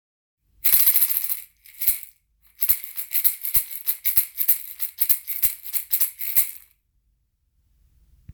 ダンゴマラカス 小
持ち手も太くなり、中身も小豆が入りサウンドもさらにグレードアップしました。高齢の方やお子様にも持ちやすく、小豆の切れの良いサウンドは、本格演奏からカラオケまで、広くお使いになれます。ダンゴマラカスは、現地ではマンボロと呼ばれ、串ダンゴ状のポピュラーなマラカス。
素材： 実 木 あずき